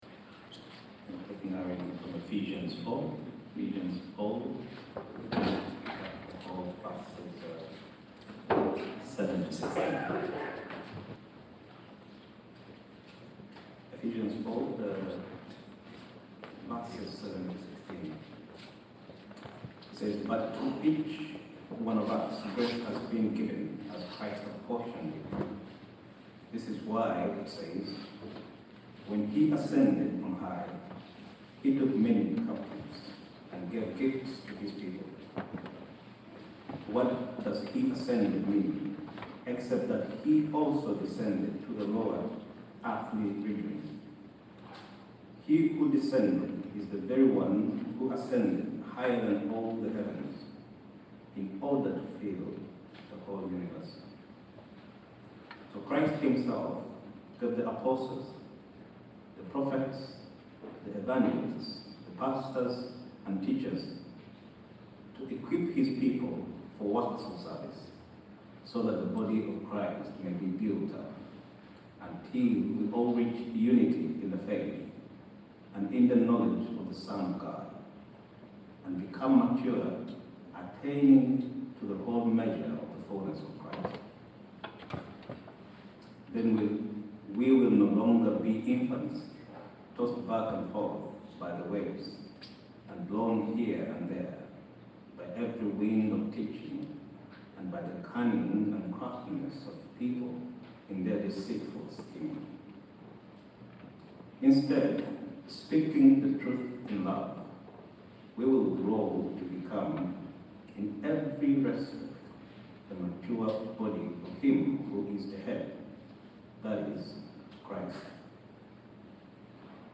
Catch-up on our latest sermons all in one place.